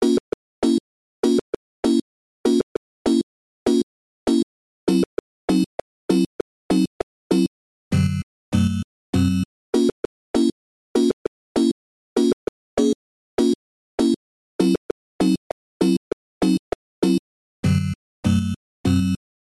sustain of the notes is low on purpose, but I'm not sure if that's what you mean since I'm a n00b
But it turns out it was the interpolation, which I turned off, and now it sounds much more crisp...